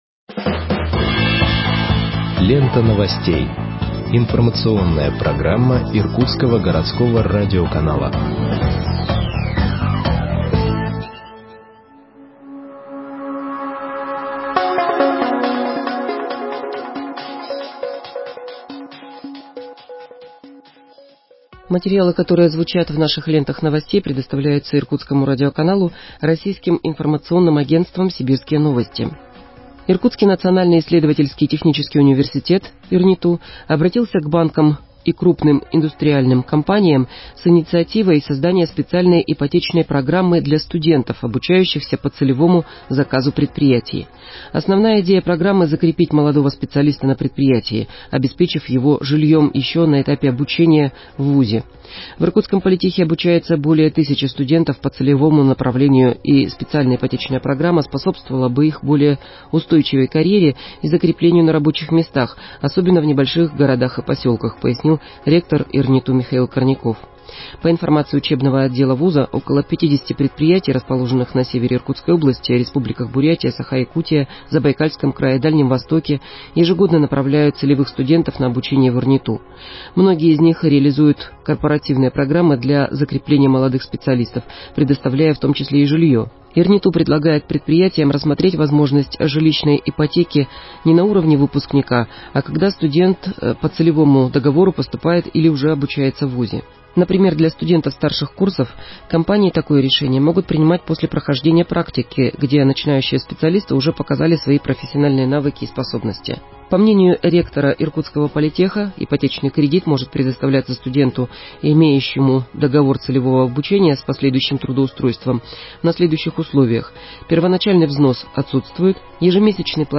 Выпуск новостей в подкастах газеты Иркутск от 30.06.2021 № 1